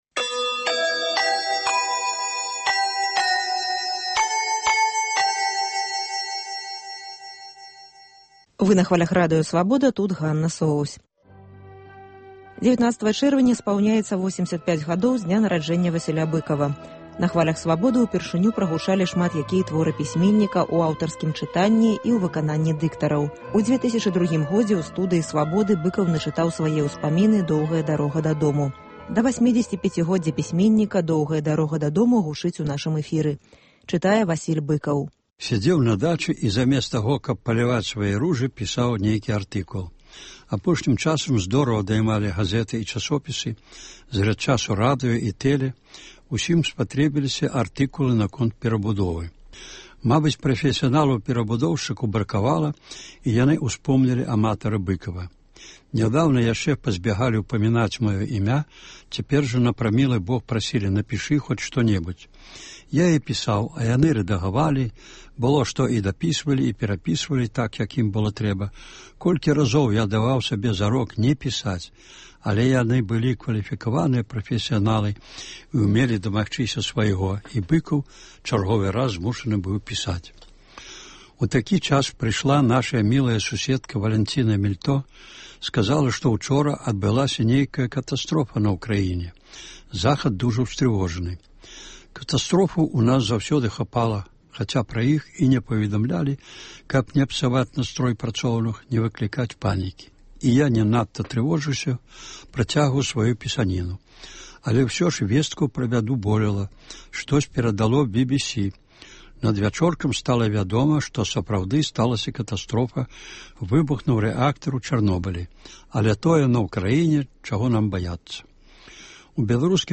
Сёлета ў чэрвені штодня ў нашым эфіры гучыць “Доўгая дарога дадому” ў аўтарскім чытаньні. Сёньня – частка 13-ая.